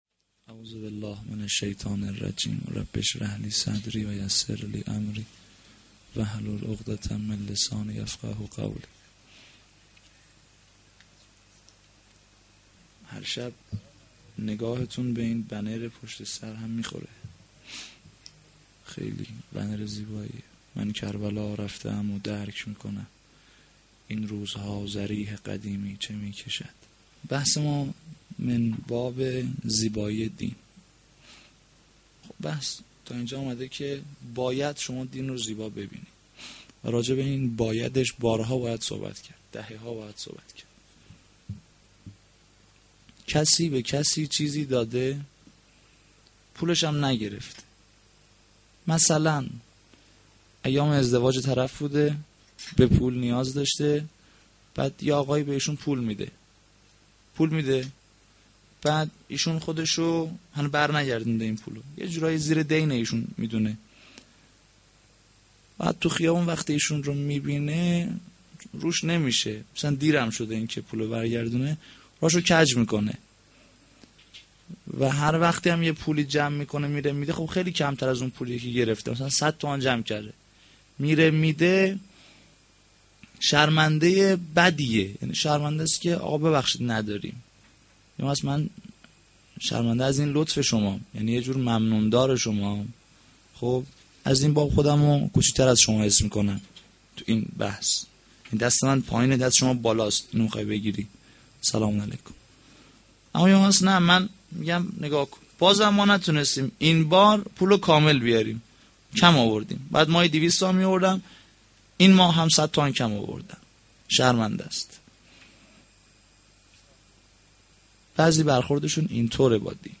sh-6-moharram-92-sokhanrani.mp3